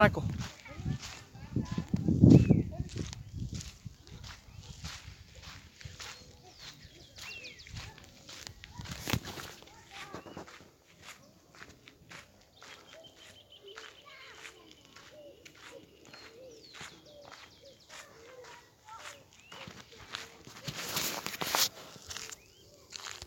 Large Elaenia (Elaenia spectabilis)
se observo y se escucho un ejemplar se le noto el vientre amarillento. en el degundo 2 y 7 se escucha el canto
Province / Department: San Luis
Condition: Wild
Certainty: Recorded vocal